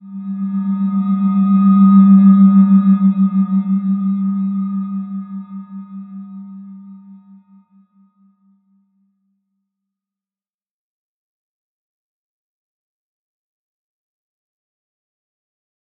Slow-Distant-Chime-G3-f.wav